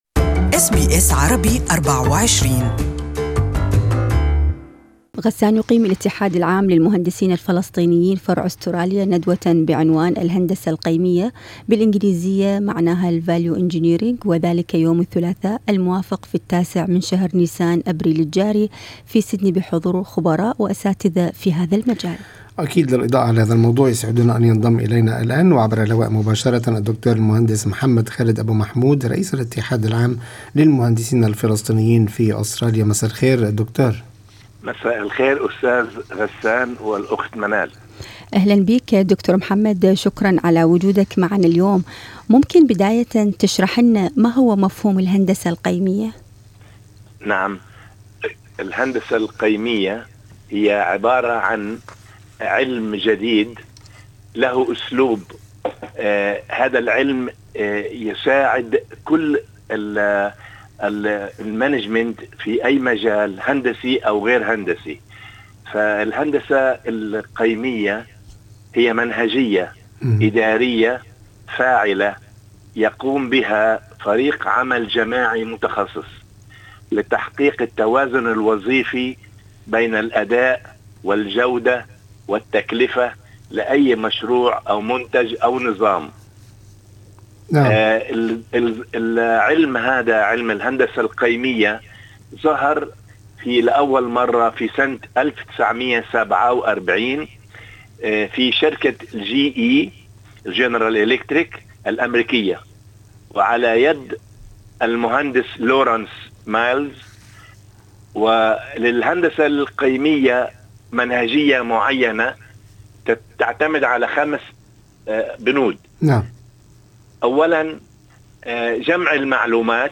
This interview is available in Arabic